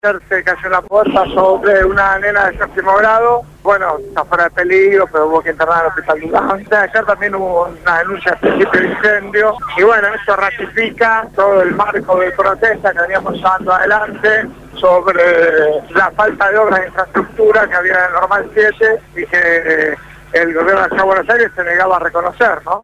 Quién participó del abrazo al Normal 7- habló esta mañana con el programa «Punto de Partida» (Lunes a viernes de 7 a 9 de la mañana) por Radio Gráfica FM 89.3